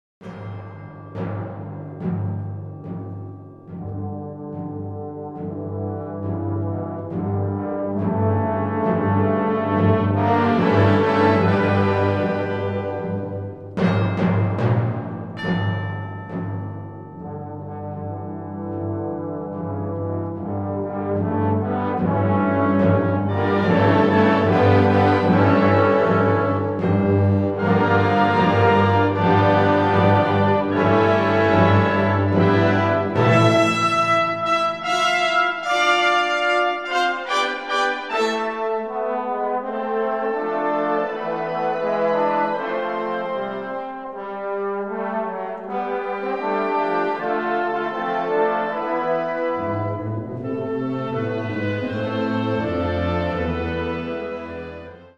Categoría Banda sinfónica/brass band
Subcategoría Suite
Instrumentación/orquestación Ha (banda de música)
con ritmos estridentes y una audaz armonía del siglo XX.